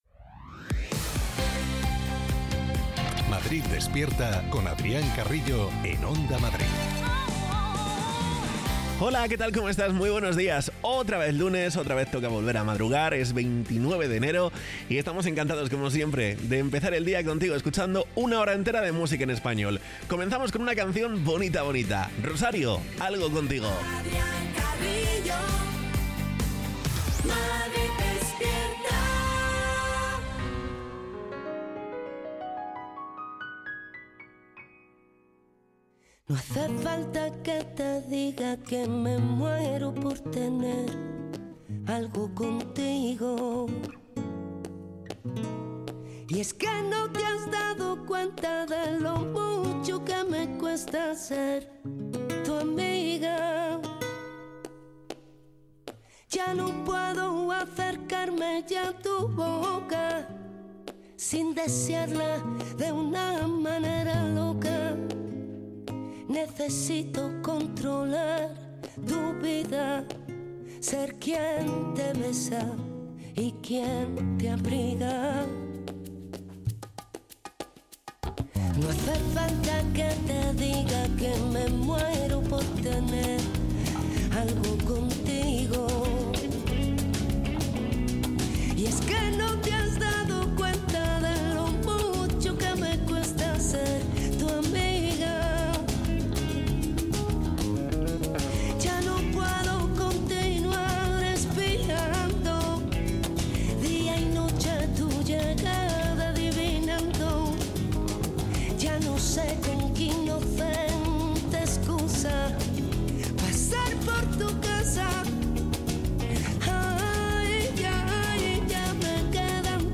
Morning show